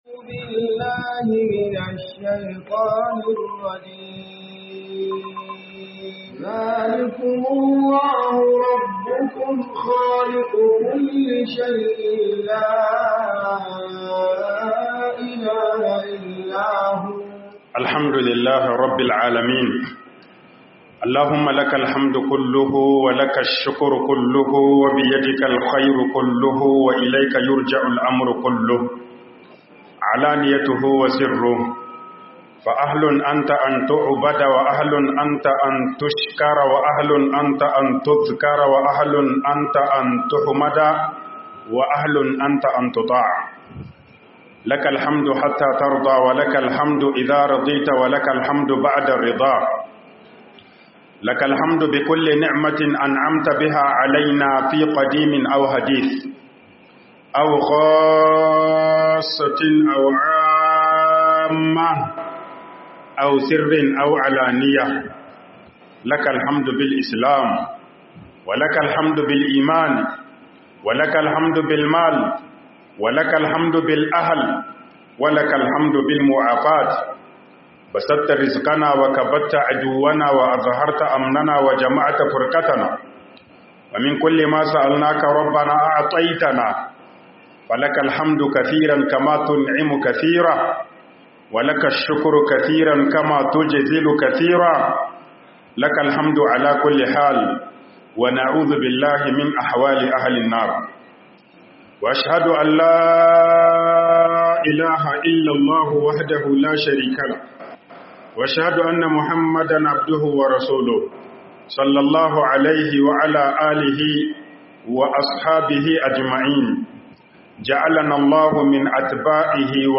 MUHADARA A NIAMEY 02